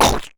icebreak.wav